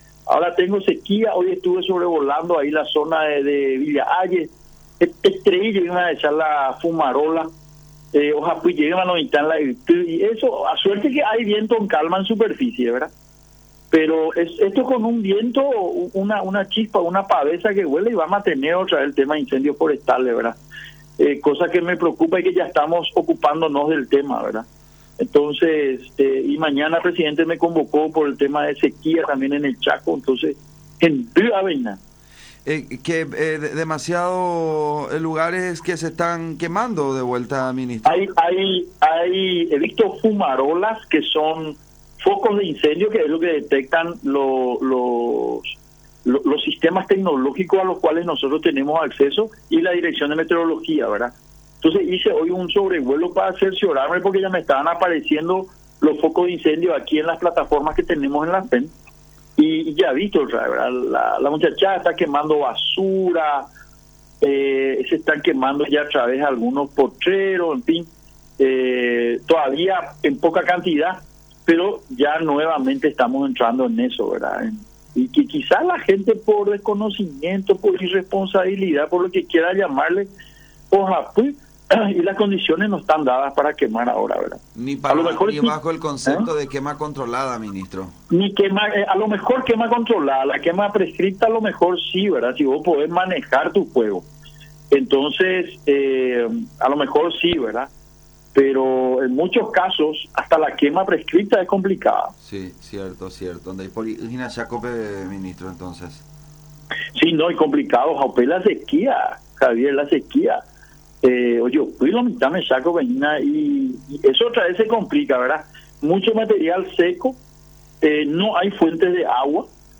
“Ahora tengo sequías, hoy estuve sobrevolando la zona de Villa Hayes, ha ahecha hetaitereí jeyma la fumarola ohapyjeyma lomitã la yty, suerte que hay viento en calma en superficie, pero esto con un viento o una chispa que vuele vamos a tener otra vez el tema de incendios forestales” indicó Roa en contacto con La Unión R800 AM.